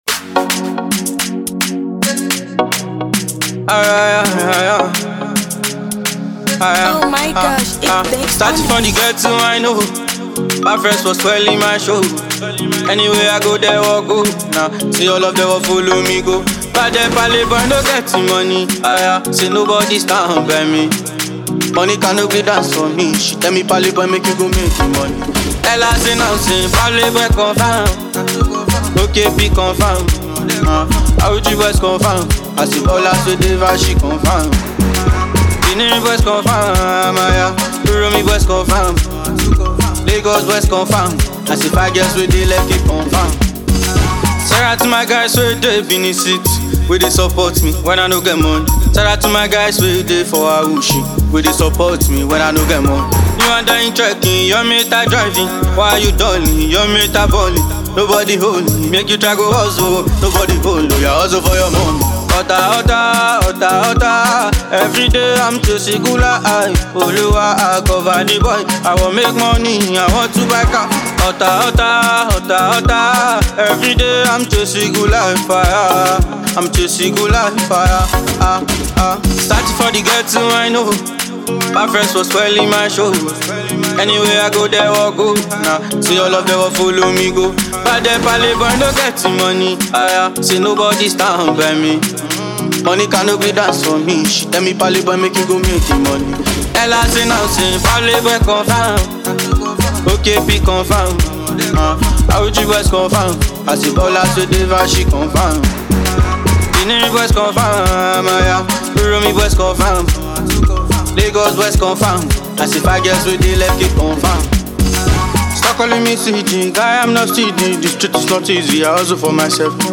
usual melodic Vocal